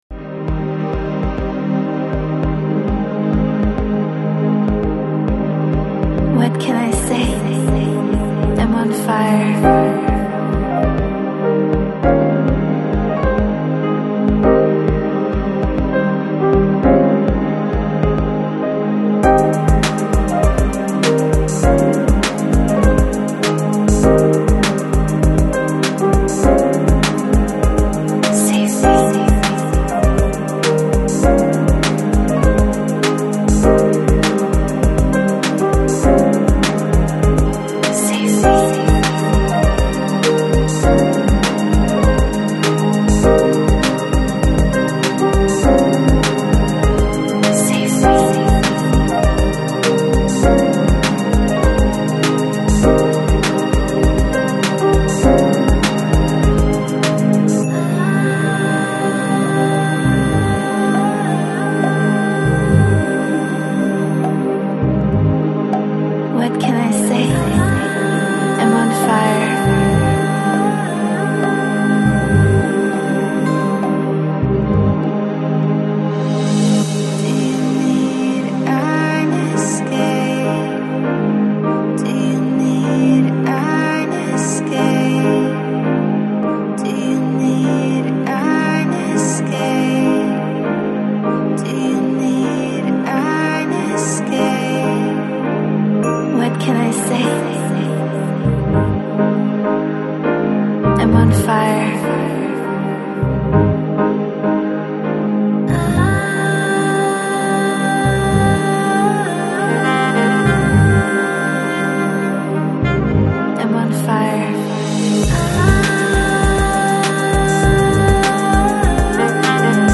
Жанр: Balearic, Downtempo